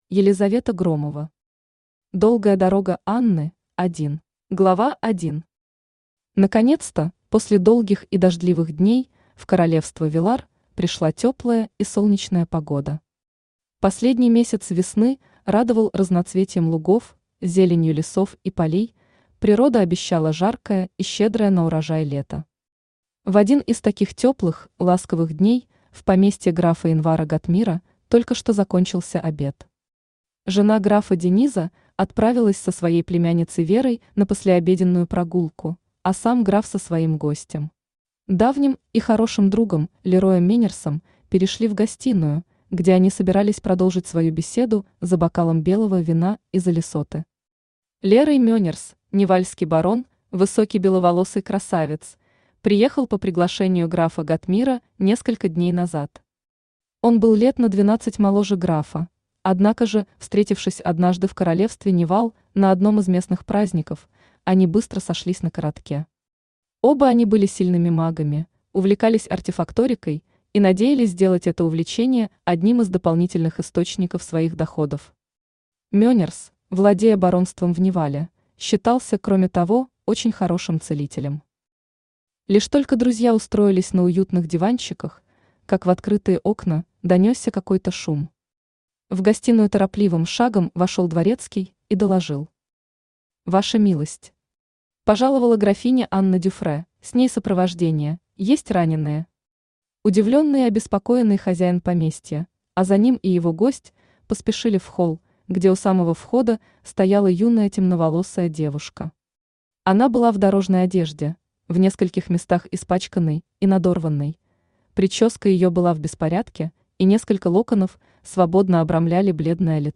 Аудиокнига Долгая дорога Анны – 1 | Библиотека аудиокниг
Aудиокнига Долгая дорога Анны – 1 Автор Елизавета Громова Читает аудиокнигу Авточтец ЛитРес.